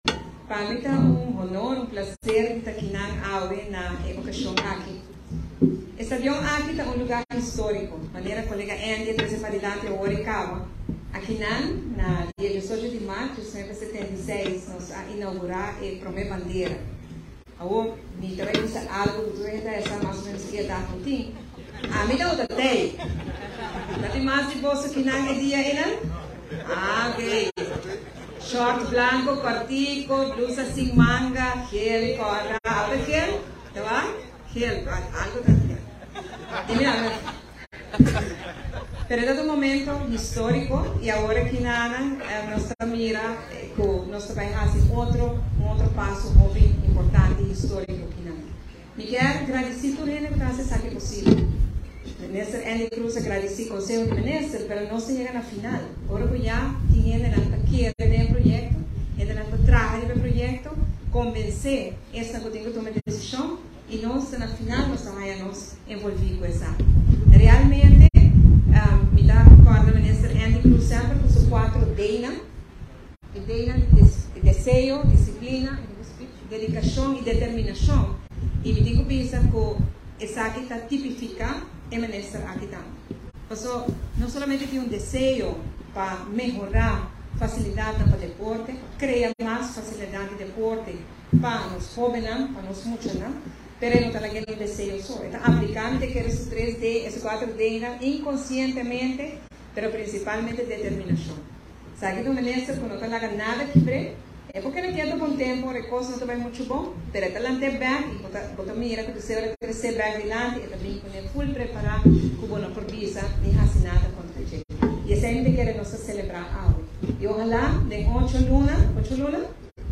Prome Minister di Aruba Evelyn Wever Croes tambe tabata presente na e anuncio oficial di e renobacionnan cu lo tuma luga pa e stadion Guillermo Prospero Trinidad. E mandatario ta recorda tempo cu e tabata mucha e tabata presente na momento cu pa prome biaha Aruba a hisa su bandera, Sigur un momento historico pa Aruba y awor lo sigui traha riba dje pa e keda bibo. momento cu pa prome biaha Aruba a hisa su bandera, Sigur un momento historico pa Aruba y awor lo sigui traha riba dje pa e keda bibo.